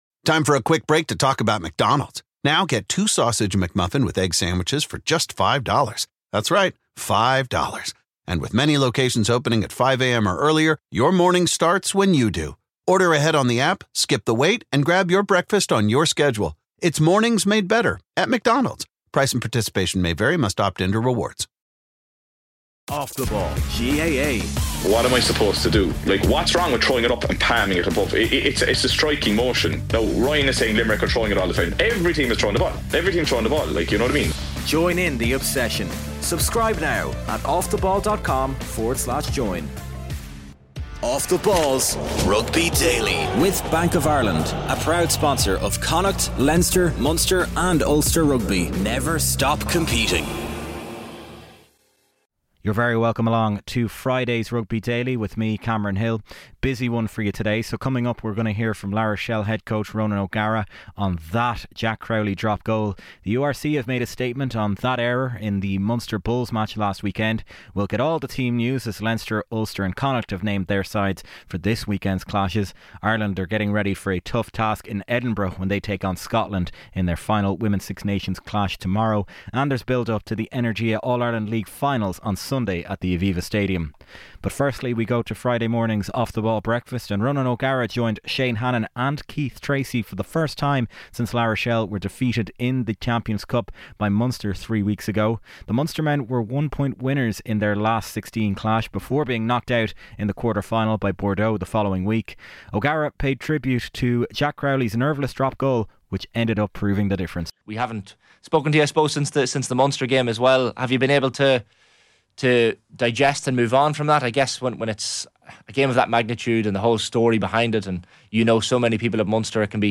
From legends interviews to live roadshows, analysis, depth charts, reviews, and weekly news.